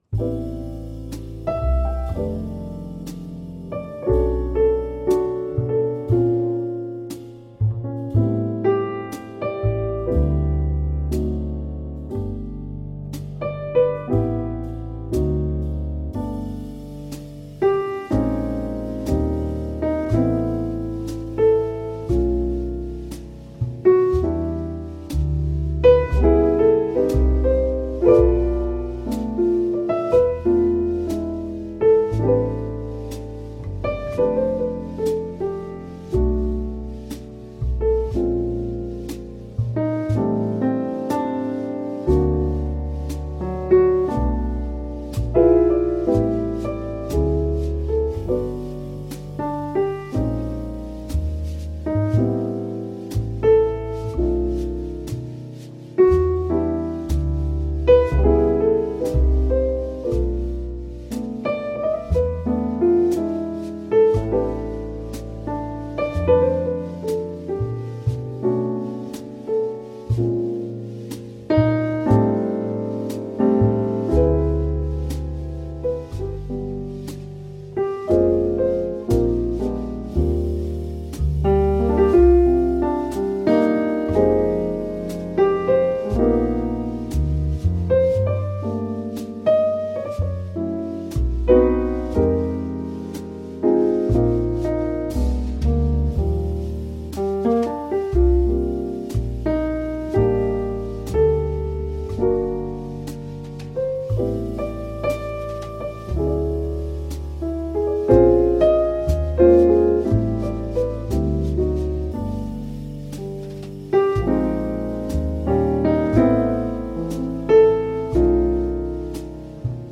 piano trio
• Performed and transcribed in the standard key of G major
• Ballad tempo at BPM 60